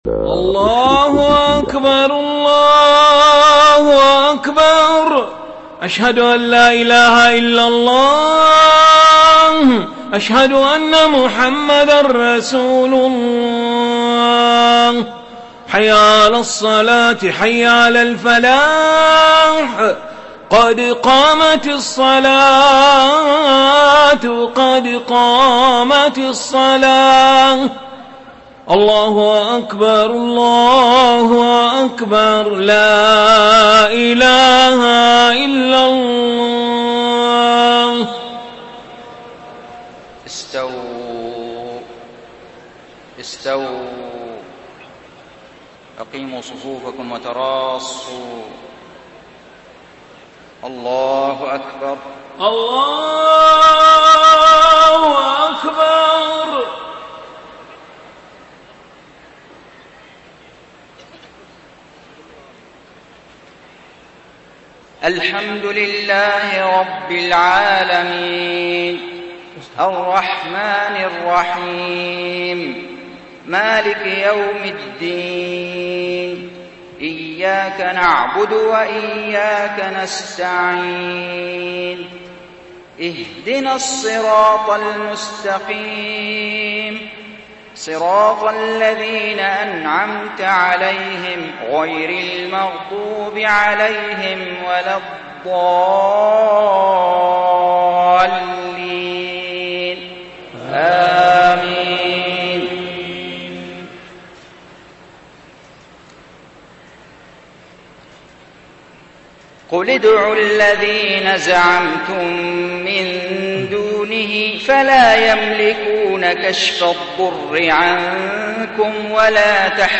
صلاة العشاء 1 ذو القعدة 1432هـ من سورة الإسراء 56-65 > 1432 🕋 > الفروض - تلاوات الحرمين